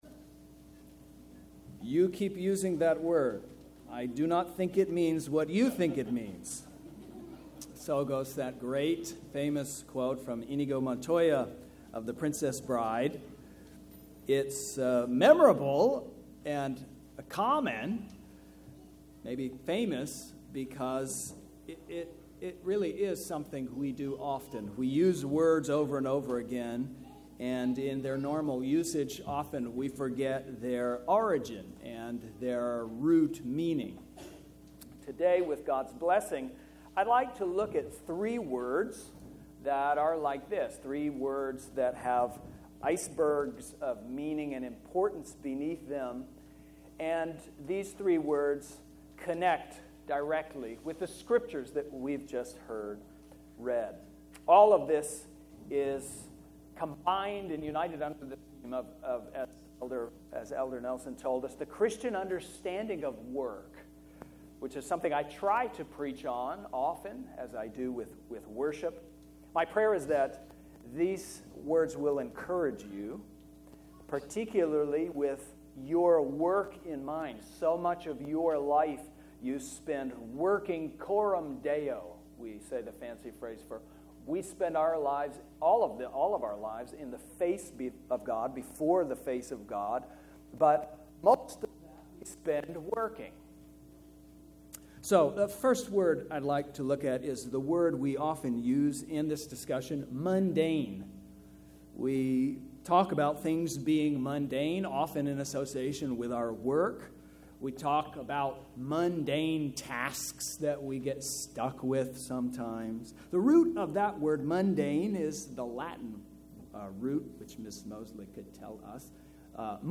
Sermons | Trinity Presbyterian Church